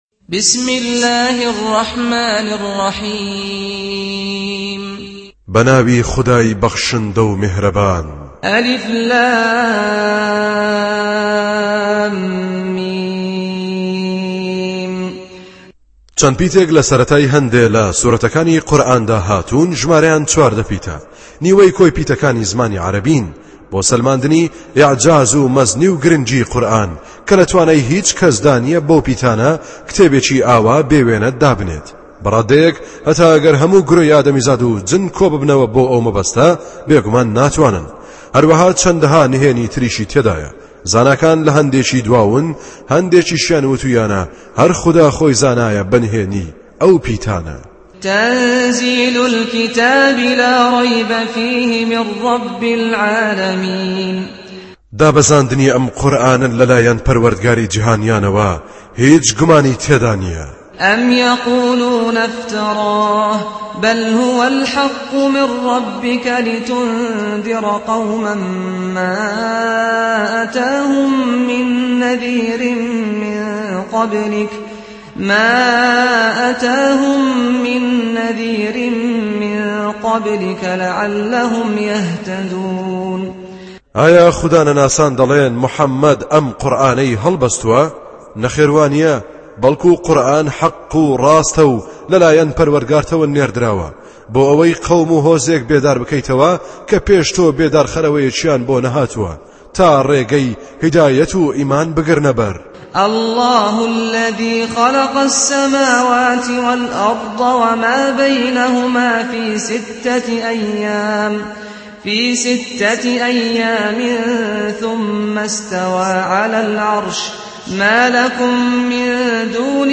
اللغة الكردية التلاوة بصوت الشيخ سعد الغامدى